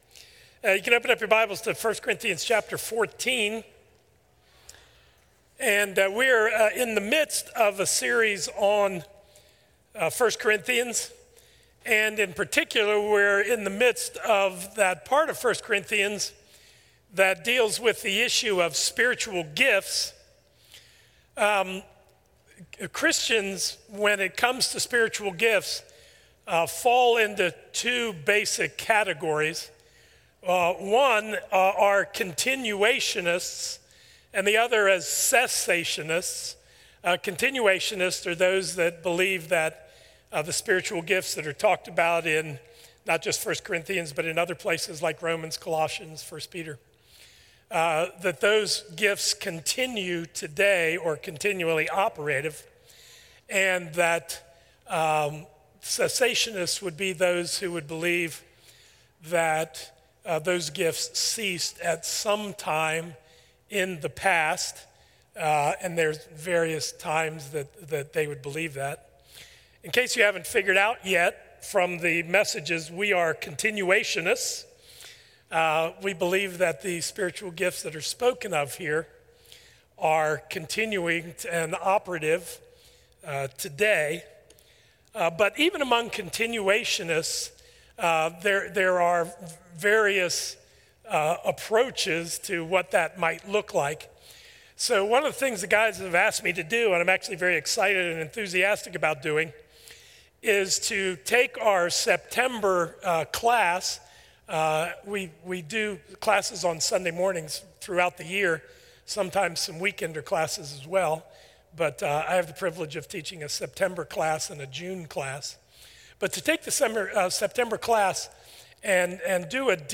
A message from the series "Behold Him."
A Sunday morning series on 1 Corinthians at Crossway Community Church.